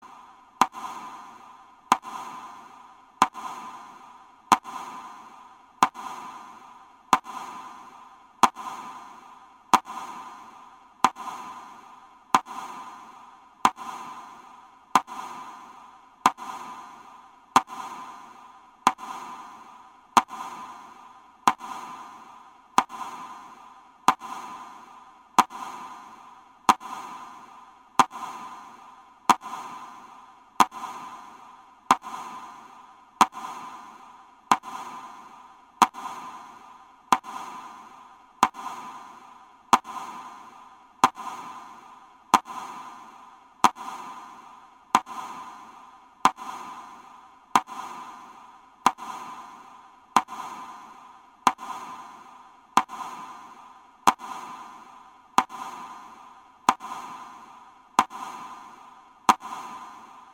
На этой странице собраны уникальные звуки Парада Победы: марши военных оркестров, рев моторов бронетехники, аплодисменты зрителей.
Звук минуты молчания на Параде Победы длительностью ровно одна минута